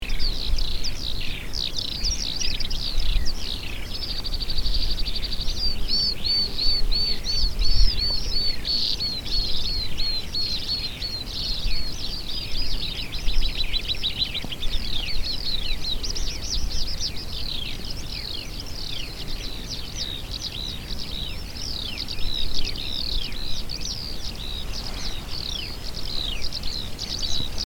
With my LS-11 Olympus voice recorder I made the following recordings of bird songs, all in stereo: